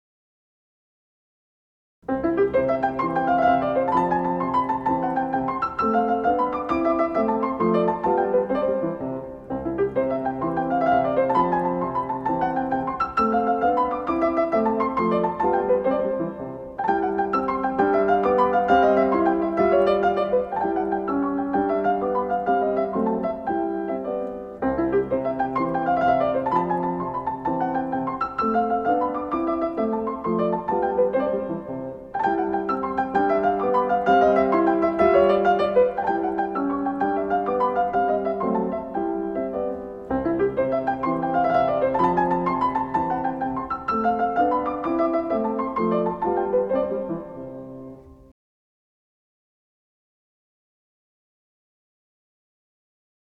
Un buen ejemplo de tema con variaciones son las variaciones para piano K. 265 de Mozart, sobre la conocidísima canción “Ah, vous dirais-je maman”.